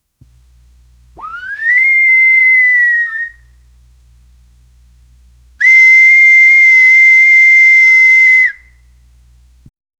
Vintage police whistle blowing loud
vintage-police-whistle-bl-dqjooei4.wav